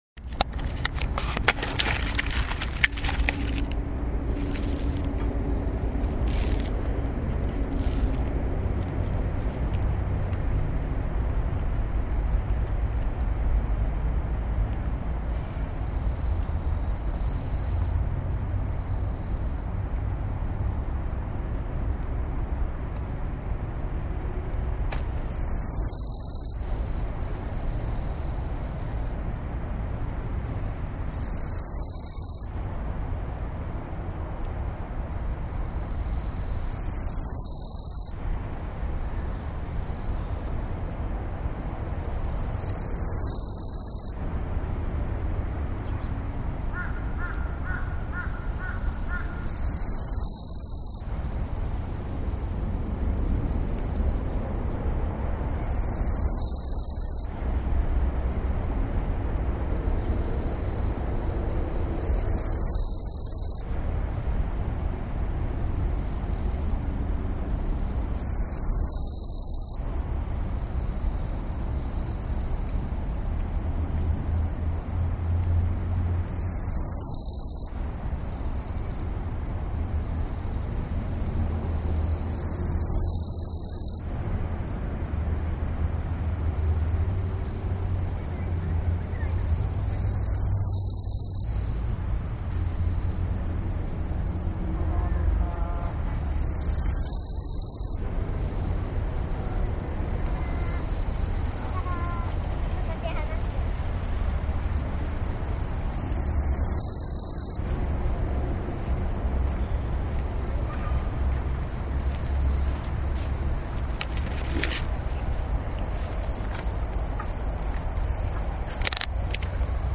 ２階の花壇 花壇見てるとセミの声が 012_110721Vせみ・・ １階の広場におり ゴウヤ を ゴーヤのすぐ裏（北）で、「紫金山キリギリス合唱／セミも加わり」 015_110721キリギリス 午後の部 １３時３０分 どえらい参加者！！